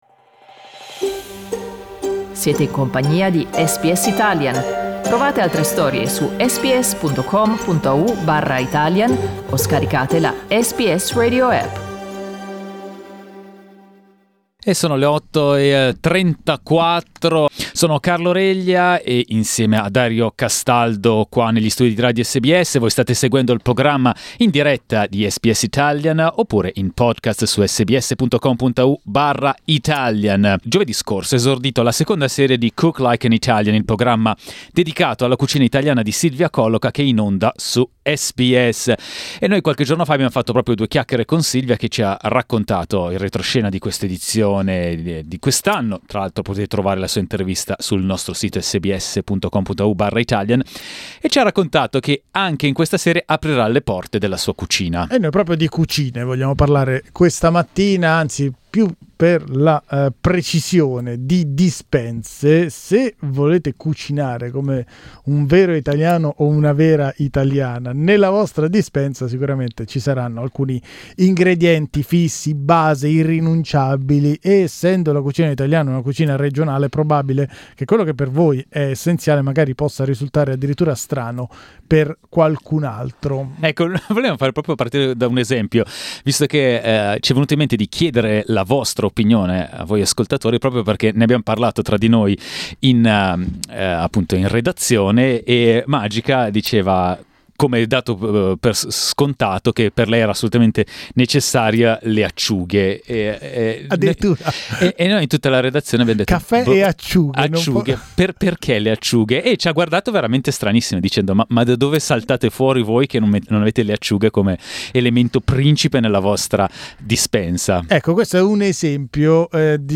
Abbiamo chiesto agli ascoltatori e alle ascoltatrici di intervenire in diretta su SBS Italian per condividere consigli e storie su quelli che sono i loro ingredienti essenziali.